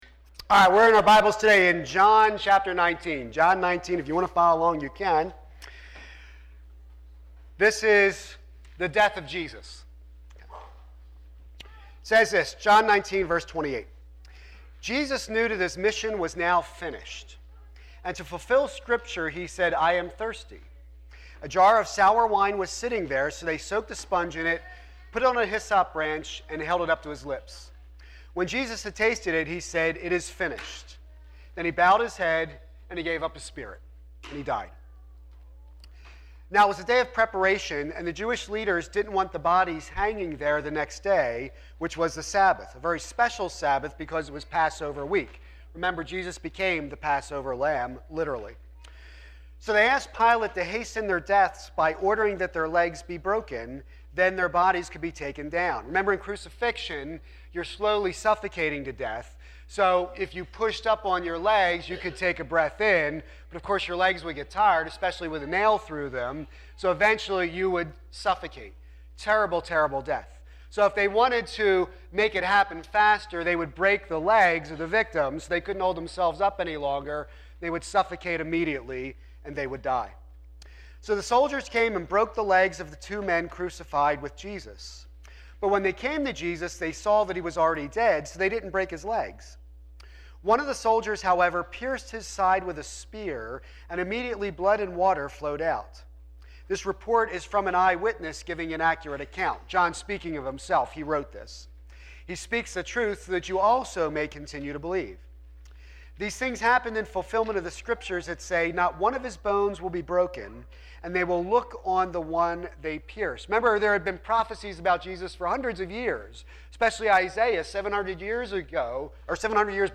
a Cross and a Grail Preacher